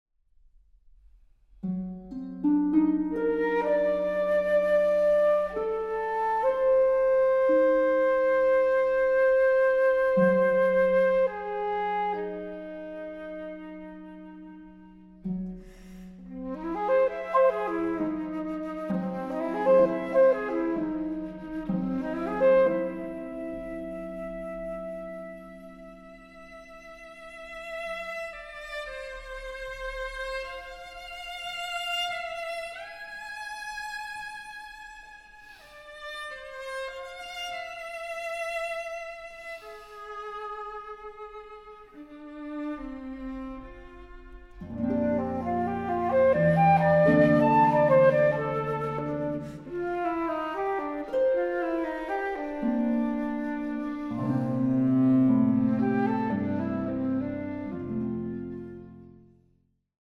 Lento, dolce rubato